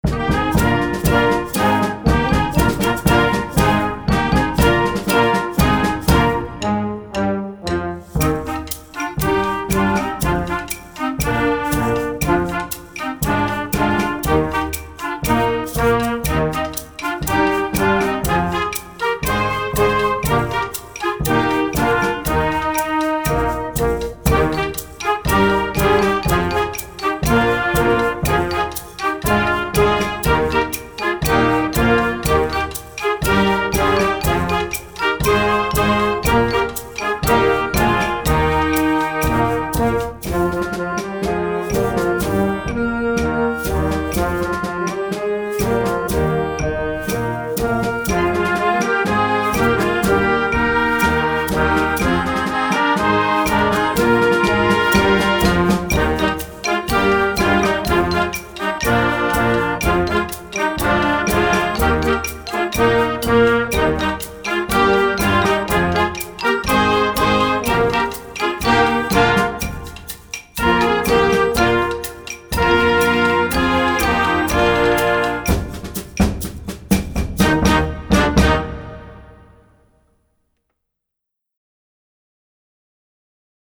Genre: Band
Implementing a fun melody with an "island style,"
Flute
Oboe
Bb Clarinet
Alto Saxophone
Bb Trumpet 1
F Horn
Trombone
Tuba
Percussion 1 (Snare Drum, Bass Drum)
Percussion 2 (Shaker, Claves)
Mallets